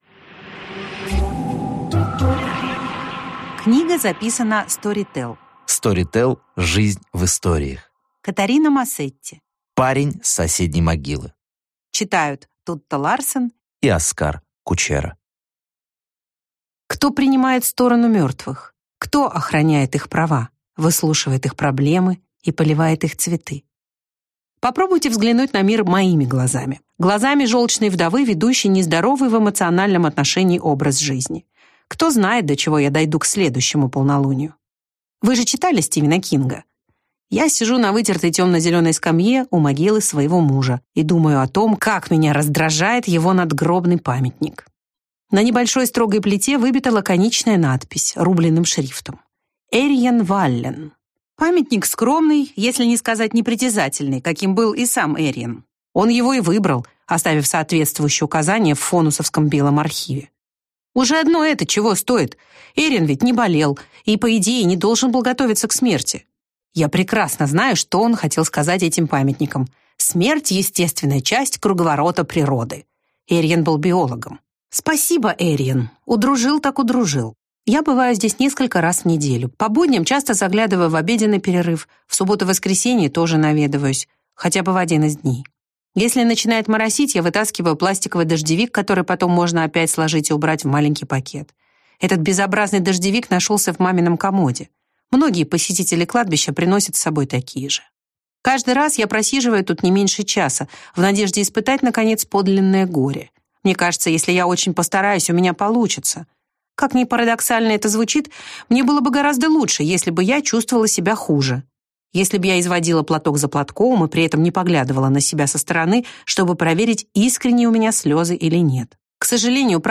Аудиокнига Парень с соседней могилы | Библиотека аудиокниг